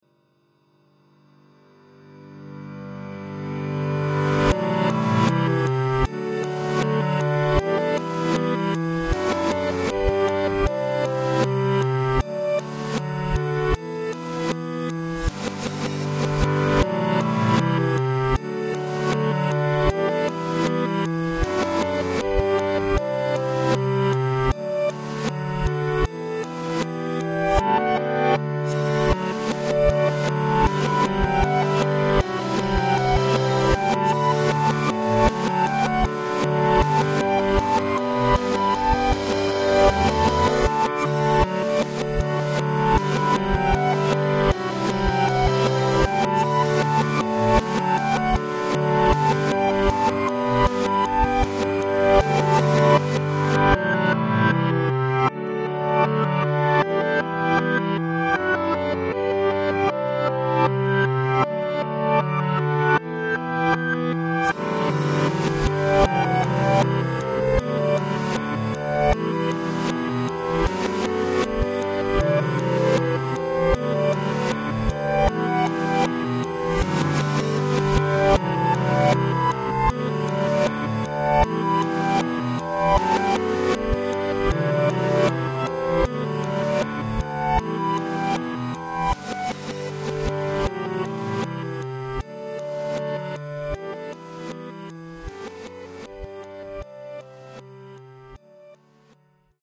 duetの逆再生(途中まで)。